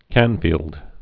(kănfēld)